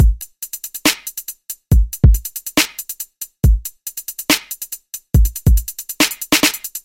陷阱或Hip Hop鼓140 BPM没有陈词滥调
描述：+++ 伴随着复古Trap或Hip Hop Bass和Timpani Loops +++ Pls comment if u use this Loop or just give some Feedback 这个Loop背后的故事： 老实说，我不喜欢Trap或808 Hip Hop，但有个人想合作，这让我想到我可以把我的旧学校声音和Trap鼓结合起来，这不是一个完整的项目，但带来一些好的Loops。